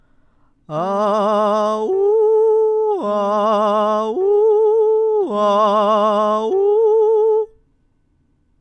２. 地声と裏声を一息で行き来する
音量注意！